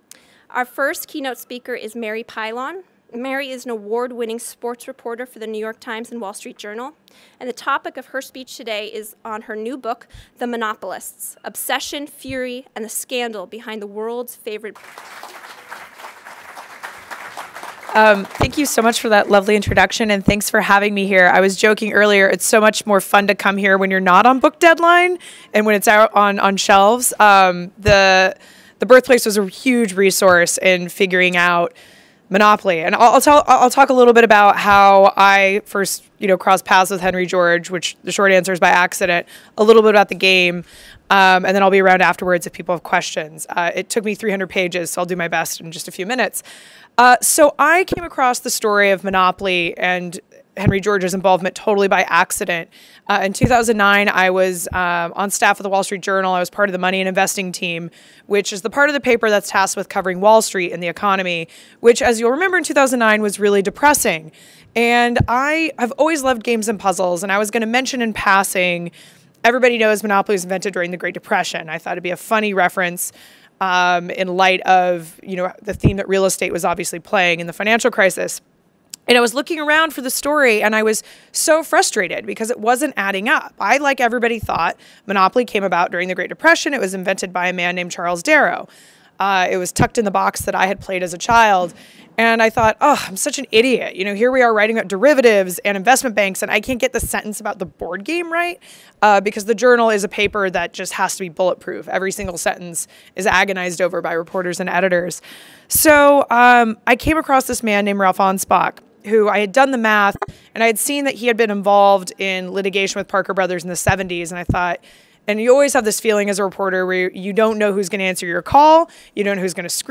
History of the Game Monopoly An audio recording of a presentation delivered at the event celebrating the re-opening of the Henry George Birthplace Museum and Archive Center, September 2014